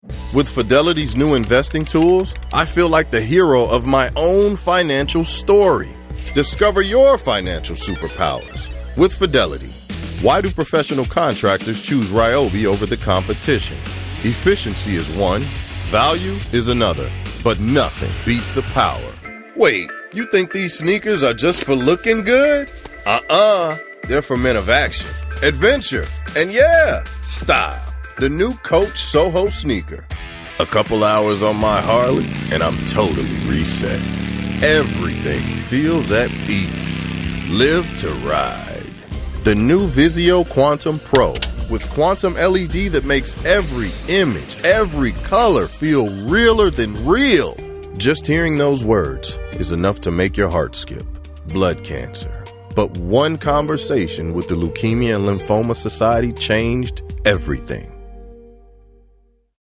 Authentic, confident, honest, treating the audience like family, with a strong, yet compassionate voice.
Commercial range demo.